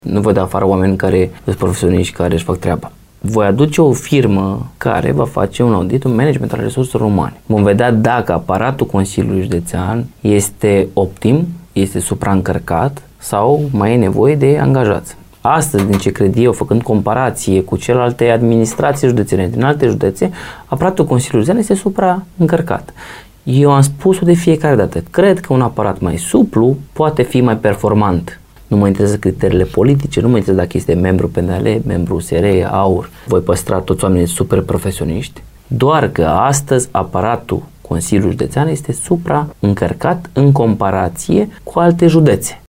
El a declarat postului nostru că va dispune efectuarea unui audit al activității din ultimii ani atunci când va prelua mandatul, în luna octombrie.